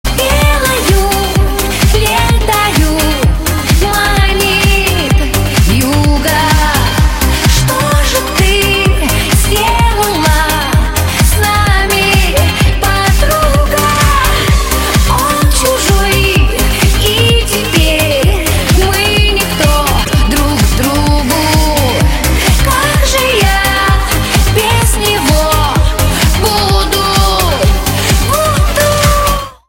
• Качество: 128, Stereo
поп
громкие
женский вокал
клубнячок